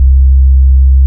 808s
Grammys Sub.wav